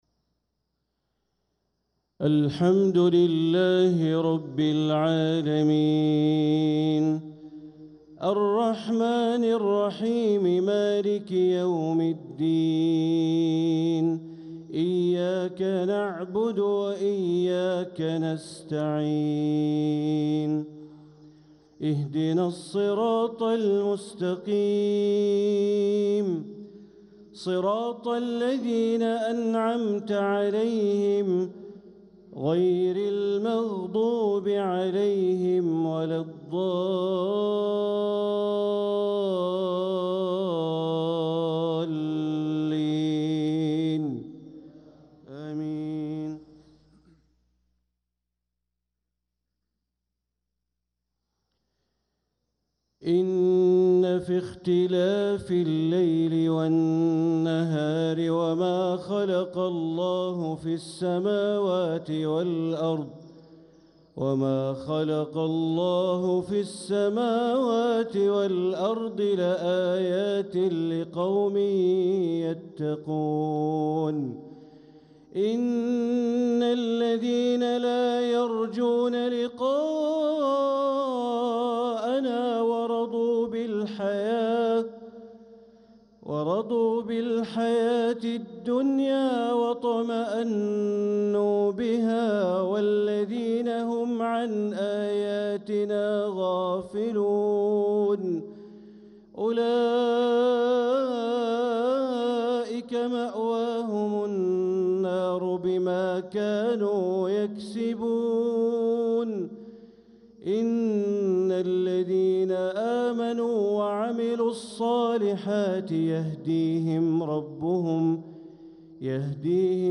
صلاة المغرب للقارئ بندر بليلة 26 ربيع الأول 1446 هـ